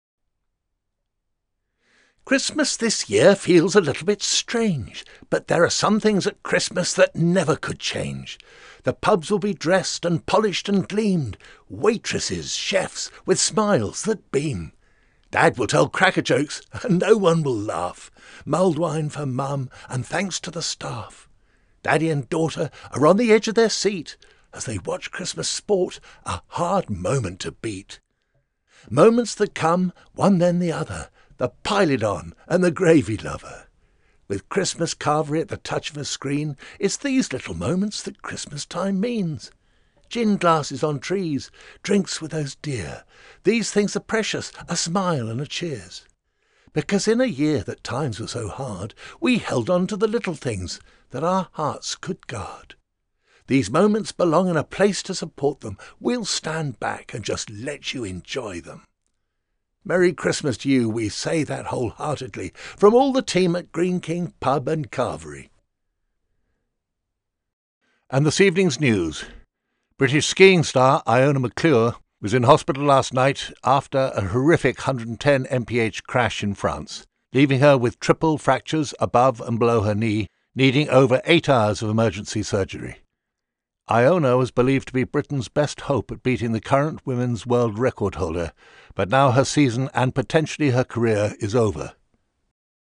Strong, bold, deep male voices bring a credible and regal sound to your voice over project.
Male Voice Over Talent, Artists & Actors
Older Sound (50+)